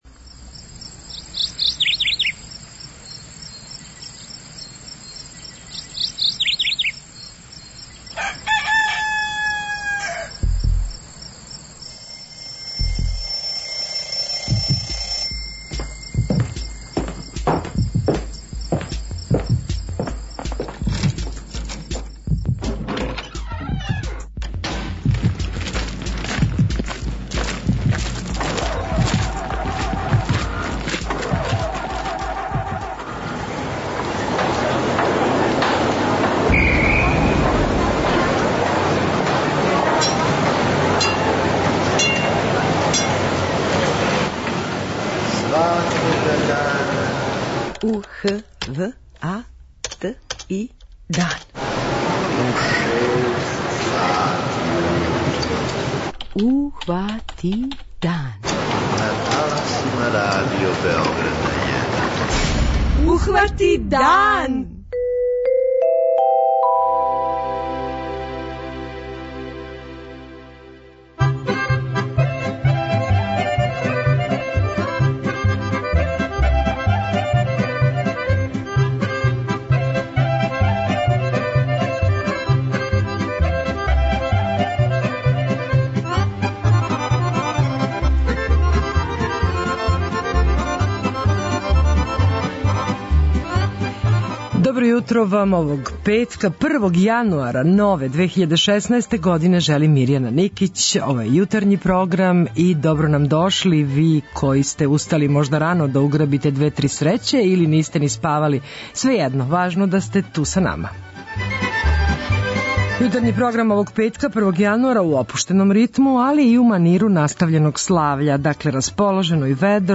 Први дан нове године хватамо уз музику и сервсине информације.